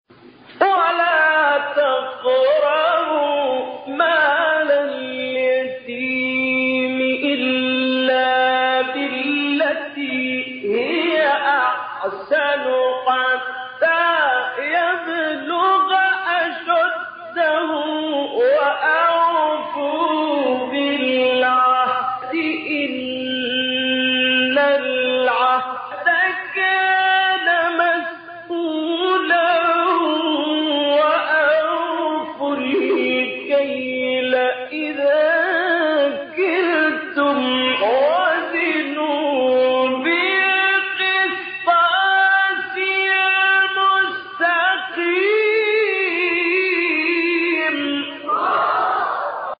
گروه شبکه اجتماعی: مقاطعی صوتی با صوت محمد اللیثی را که در مقام‌های مختلف اجرا شده است، می‌شنوید.
به گزارش خبرگزاری بین المللی قرآن(ایکنا) پنج فراز صوتی از سوره مبارکه اسراء با صوت محمد اللیثی، قاری برجسته مصری در کانال تلگرامی قاریان مصری منتشر شده است.
مقام رست